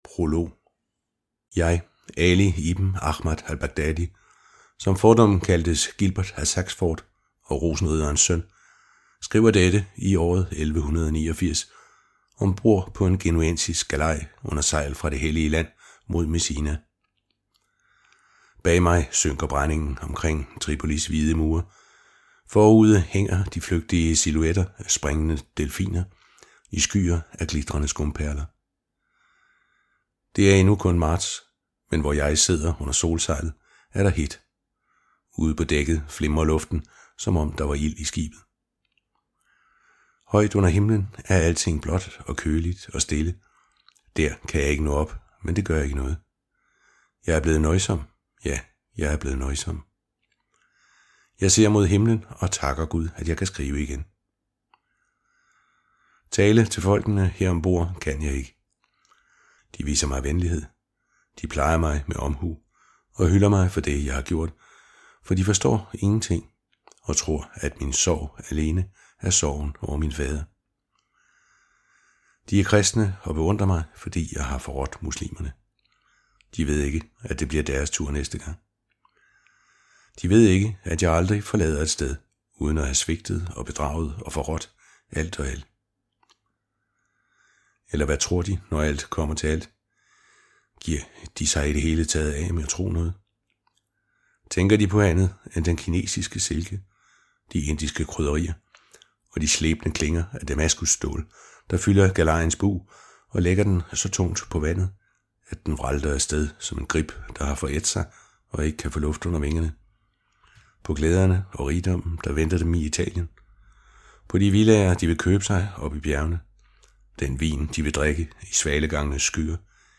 De fagre riger af Poul Hoffmann - MP3 lydbog
Hør et uddrag af De fagre riger De fagre riger Korsfarer-trilogien bind 1 Format MP3 Forfatter Poul Hoffmann Lydbog E-bog 99,95 kr.